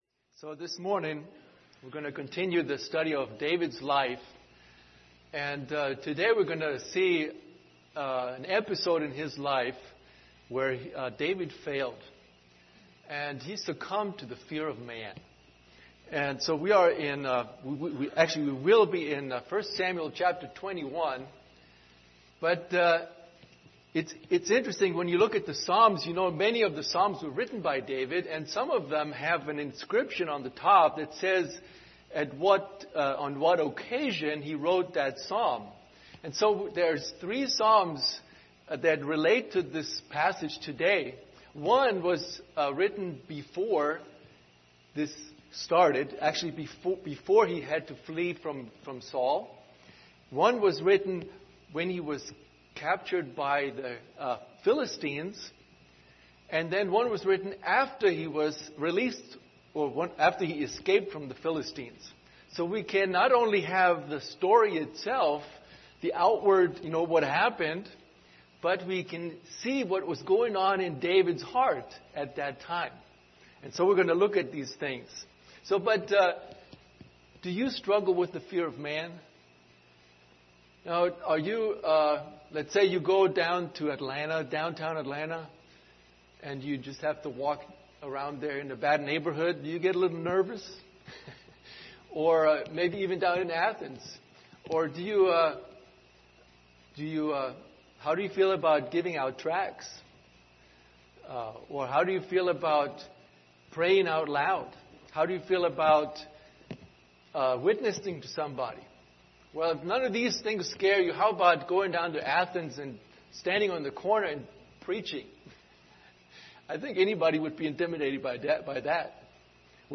Passage: 1 Samuel 21:1-9 Service Type: Sunday School Hour